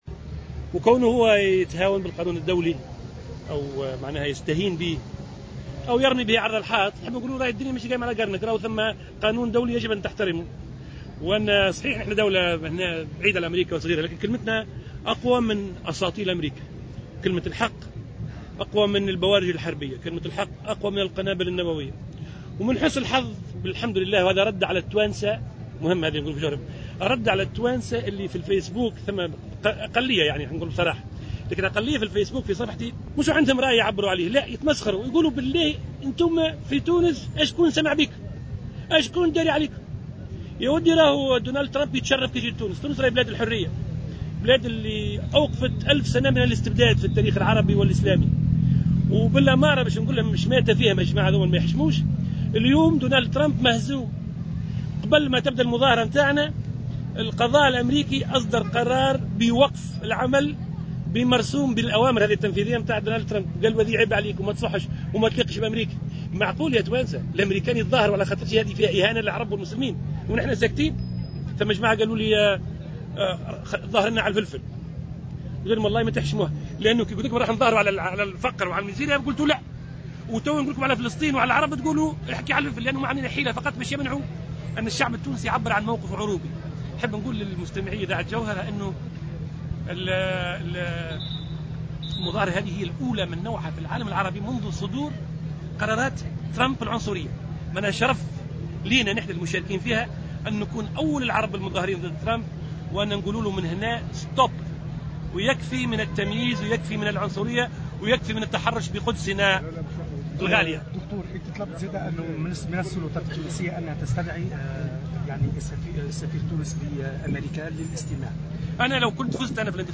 اعتبر زعيم تيار المحبة، الهاشمي الحامدي، في تصريح لمراسل الجوهرة أف أم، أن قرار الرئيس الأمريكي دونالد ترامب بنقل سفارة بلاده في فلسطين المحتلة إلى القدس يمثل تهاونا واستهانة بالقانون الدولي.
وقال الحامدي، خلال مسيرة نظمها حزبه اليوم السبت تعبيرا عن رفض مرسوم الرئيس الأمريكي دونالد ترامب بمنع دخول مواطني سبع دول ذات أغلبية مسلمة إلى الولايات المتحدة، إنه لو كان رئيسا للجمهورية التونسية بدلا من الباجي قايد السبسي، لقام باستدعاء السفير التونسي في واشنطن ودعوة الدول العربية والإسلامية إلى عقد جلسة طارئة يتم خلالها تعميم قرار سحب السفراء من الولايات المتحدة.